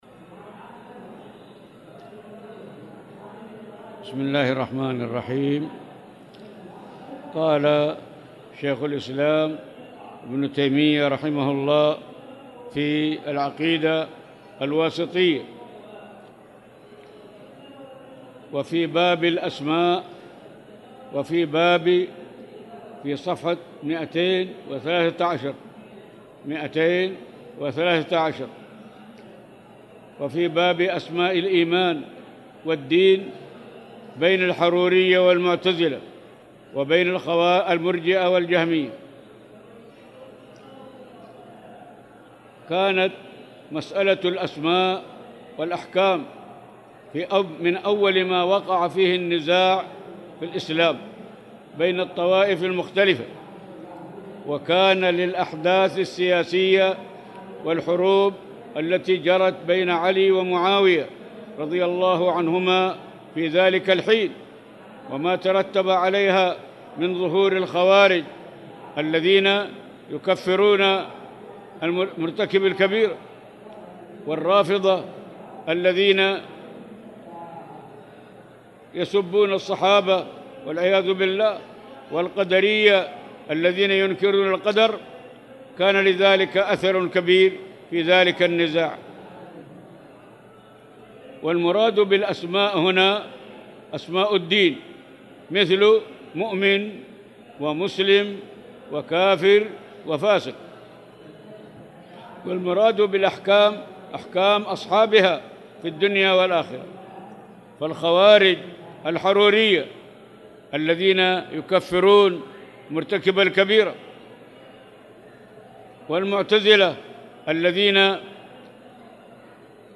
تاريخ النشر ١٨ شعبان ١٤٣٨ هـ المكان: المسجد الحرام الشيخ